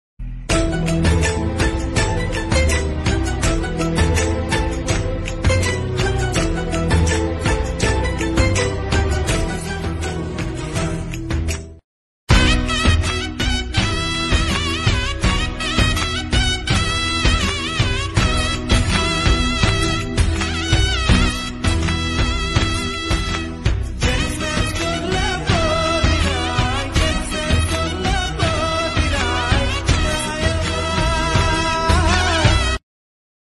Instrumental Ringtone